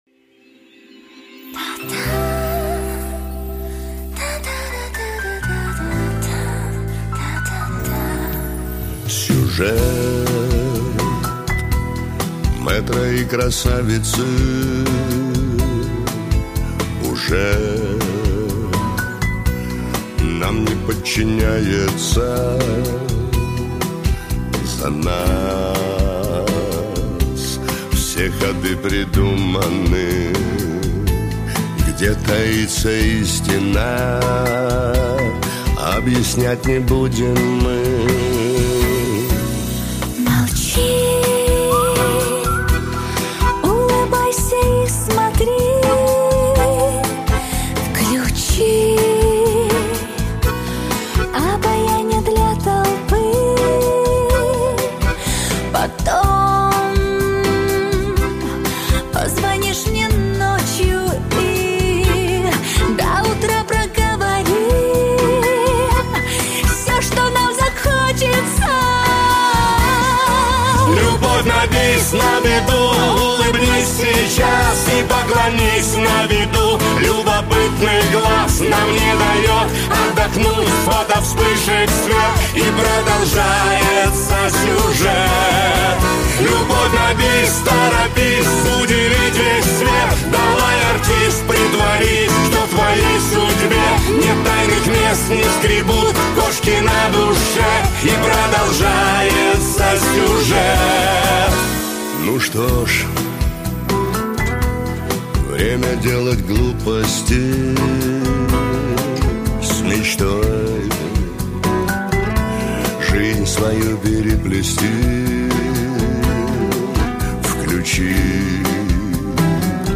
Тональность G#m
Плюсовий запис